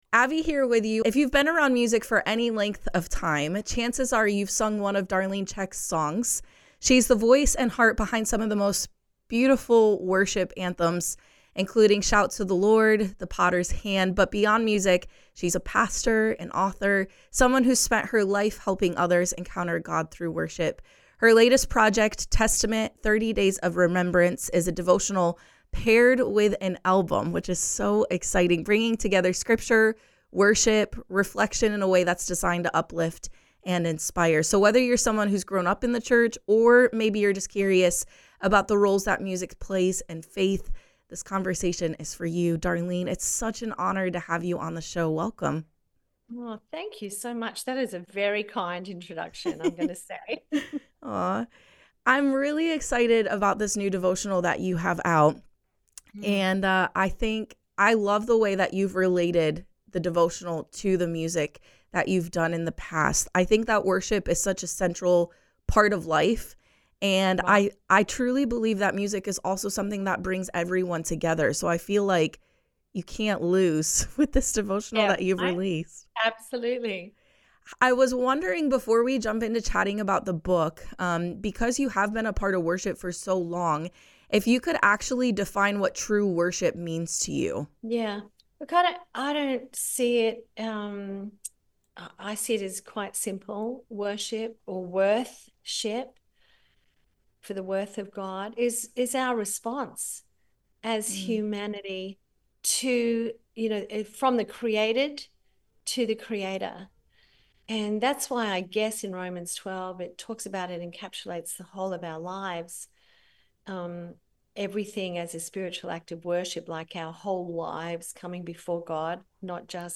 Darlene Zschech—renowned worship leader, pastor, and writer of “Shout to the Lord”—joined us on 99.9 Live FM to share about her powerful new devotional and album, Testament: 30 Days of Remembrance.
Interview-EDITED-Darlene-Zschech_01-1.mp3